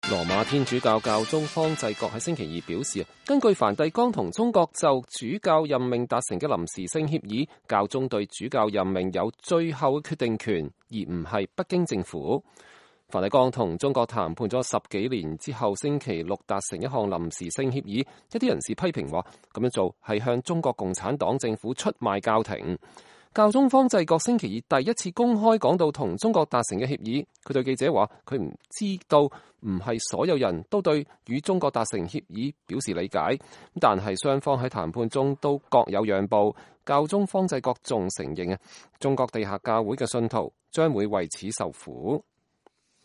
教宗方濟各2018年9月25日結束訪問愛沙尼亞後在返回梵蒂岡的飛機上對記者講話。